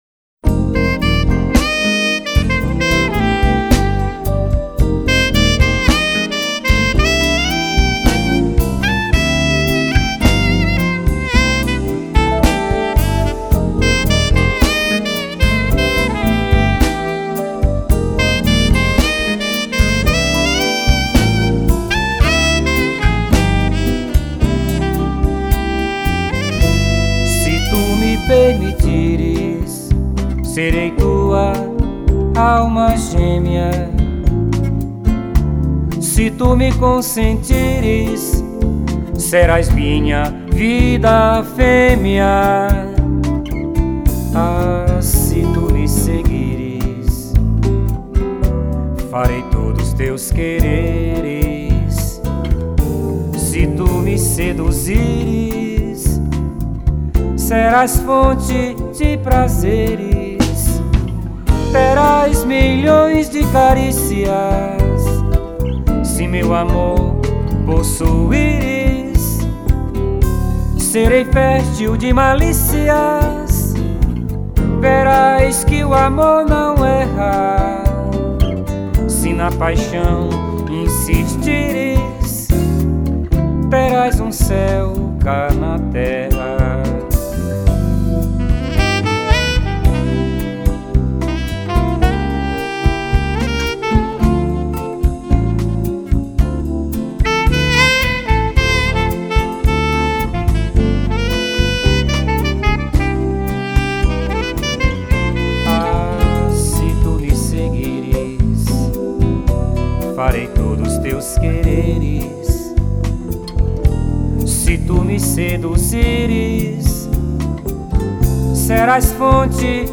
2113   03:02:00   Faixa:     Bossa nova
Bateria
Baixo Elétrico 6
Violao Acústico 6
Teclados
Sax Alto